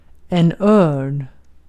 Uttal
Uttal Okänd accent: IPA: /œrn/ Ordet hittades på dessa språk: svenska Översättning Substantiv 1. kartal Andra/okänd 2. baz 3. şehbaz 4. şahbaz Artikel: en .